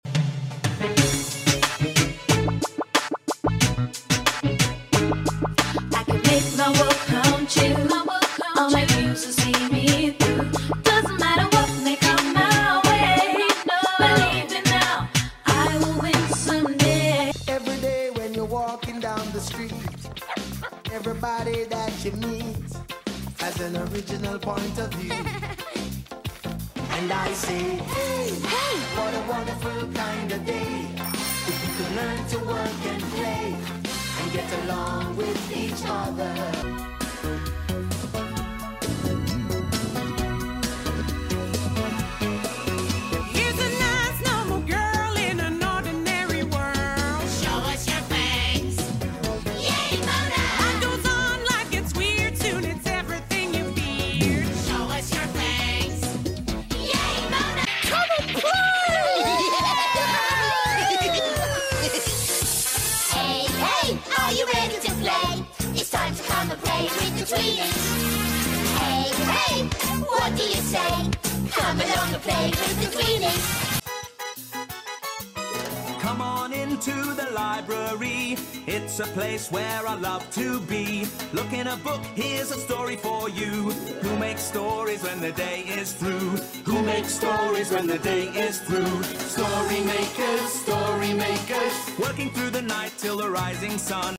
TV show intros